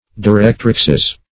Directrix \Di*rect"rix\, n.; pl. E. Directrixes, L.